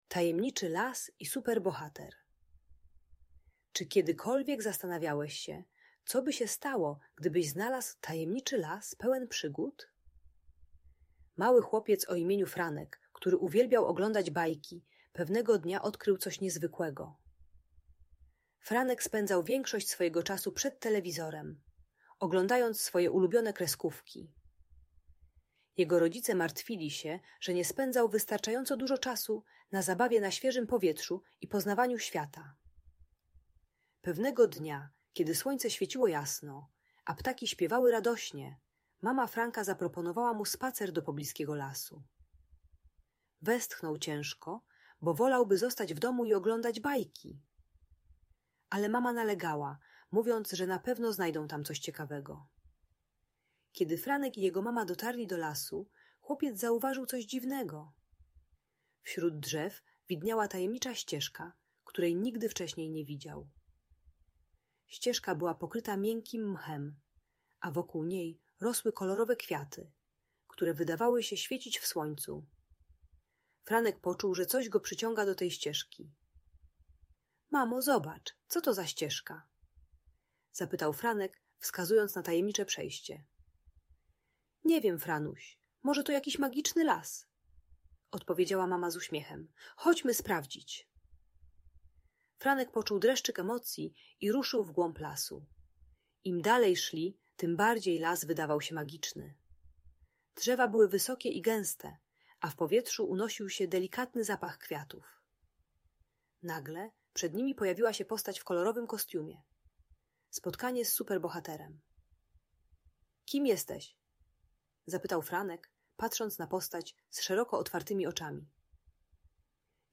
Tajemniczy Las i Super Bohater - Audiobajka dla dzieci